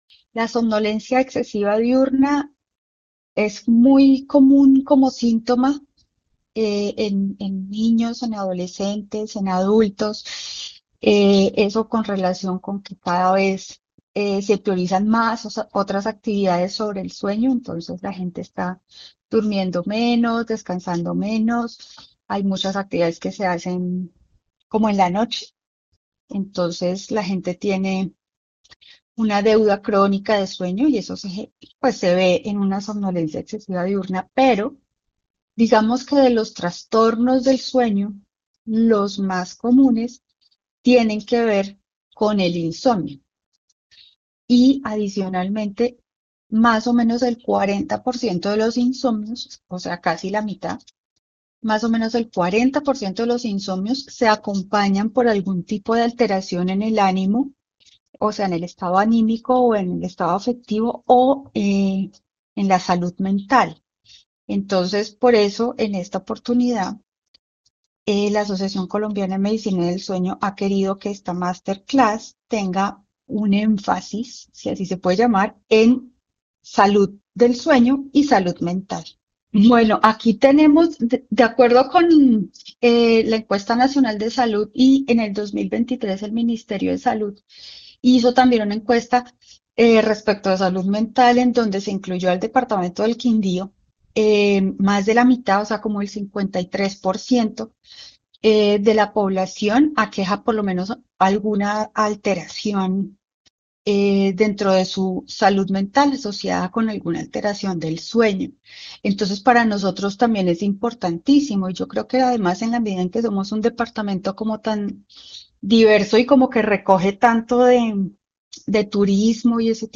Experta en sueño